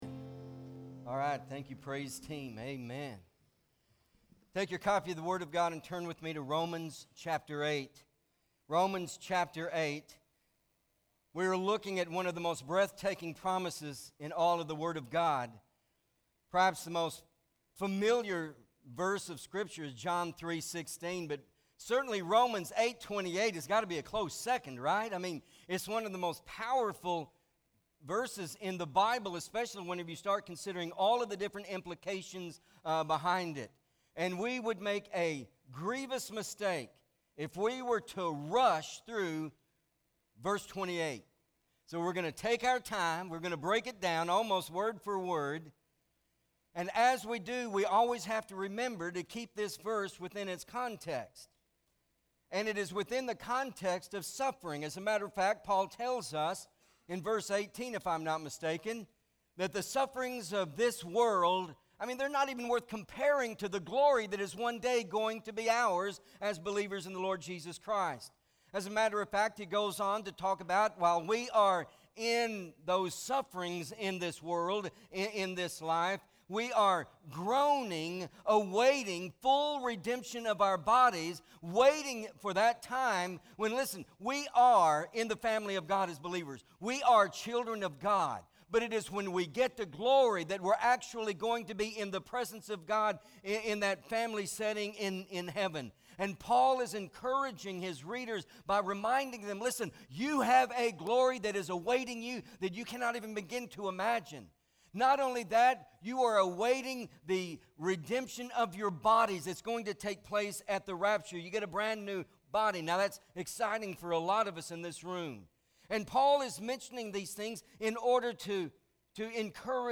– Pt. 2 MP3 SUBSCRIBE on iTunes(Podcast) Notes Sermons in this Series Roman 8:28 Not Ashamed!